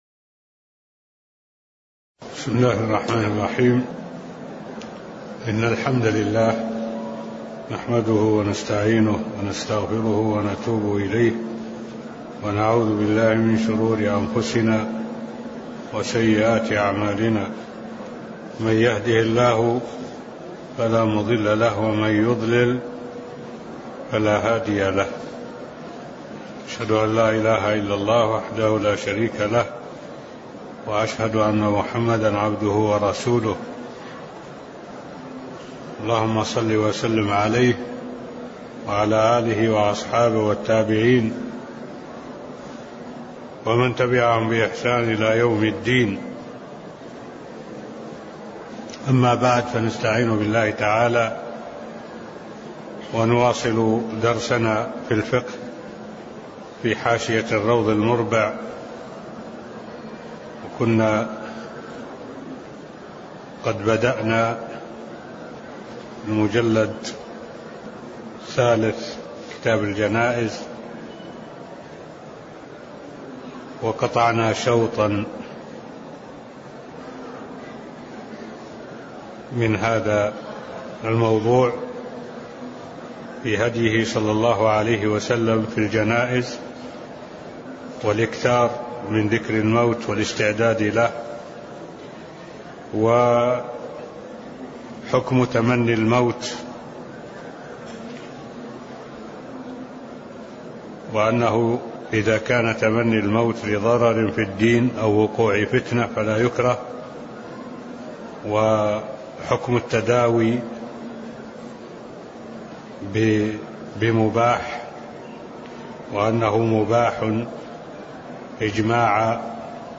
تاريخ النشر ٢ صفر ١٤٢٩ هـ المكان: المسجد النبوي الشيخ